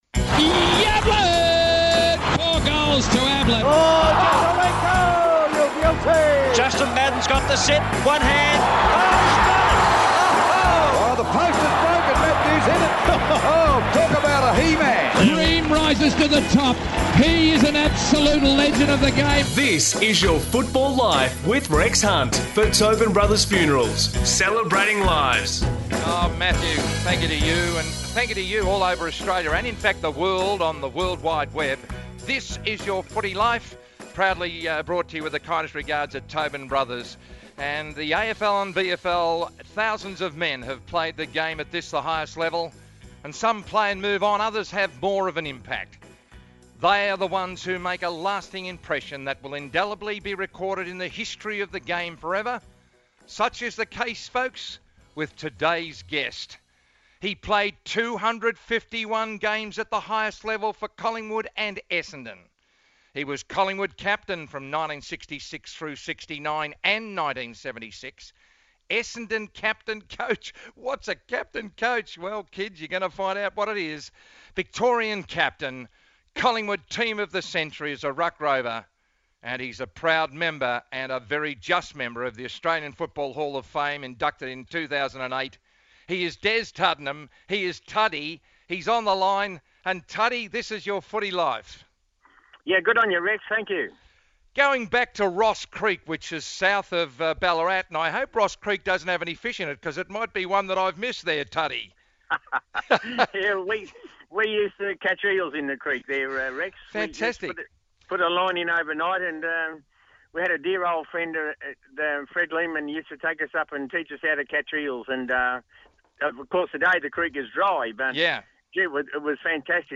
Listen to Collingwood legend Des Tuddenham join Rex Hunt to discuss his career on Your Football Life, as heard on SEN 1116.